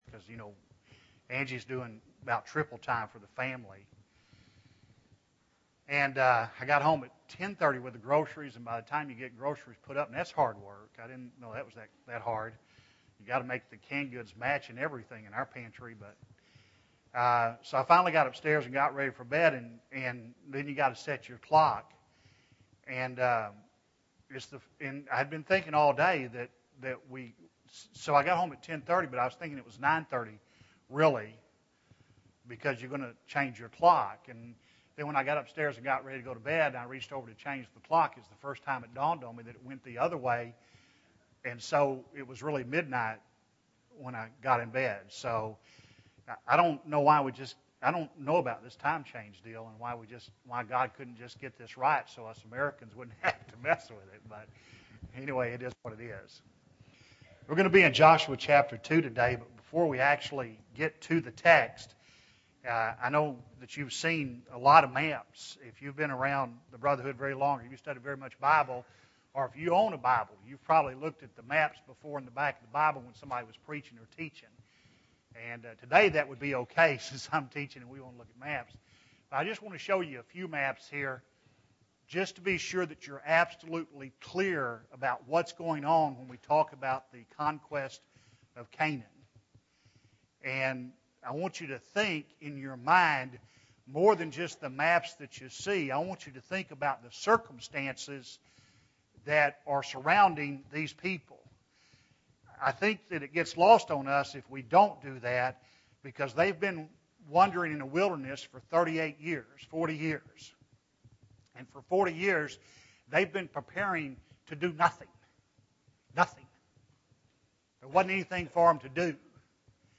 Joshua 2 (2 of 14) – Bible Lesson Recording
Sunday AM Bible Class